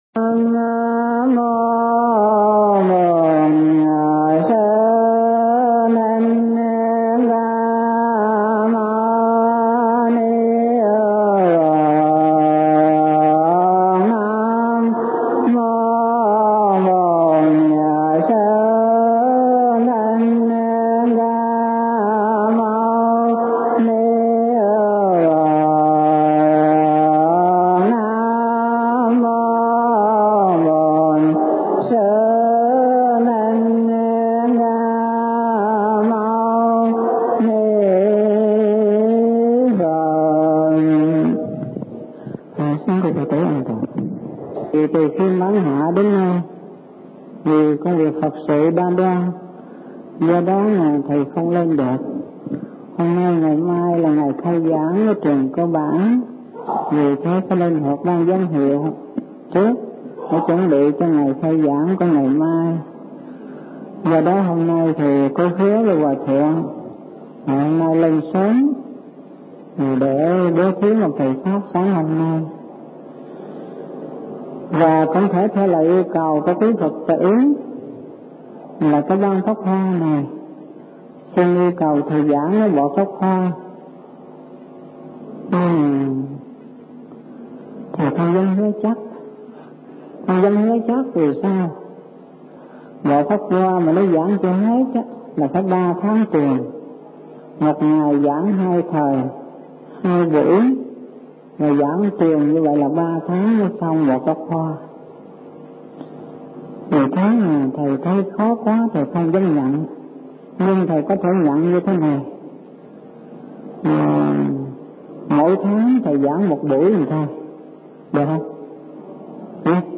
Kinh Giảng Pháp Thoại 1